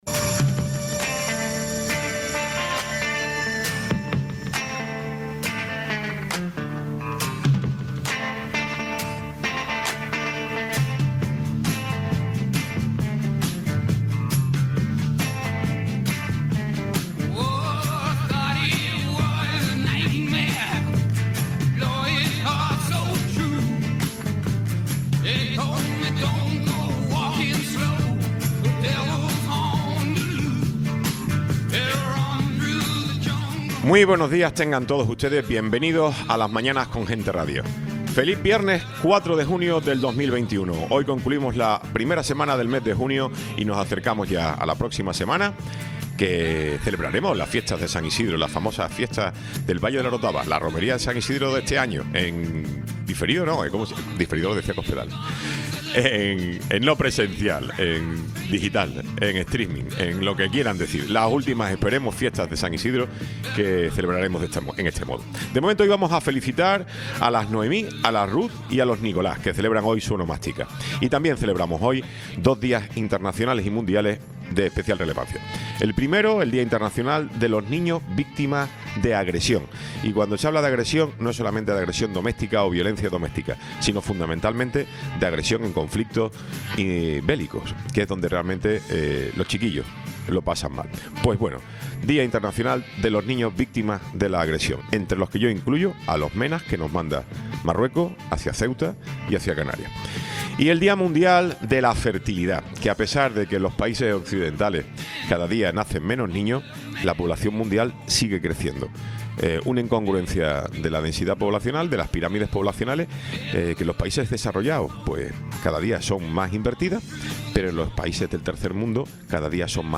Tiempo de entrevista
Tertulia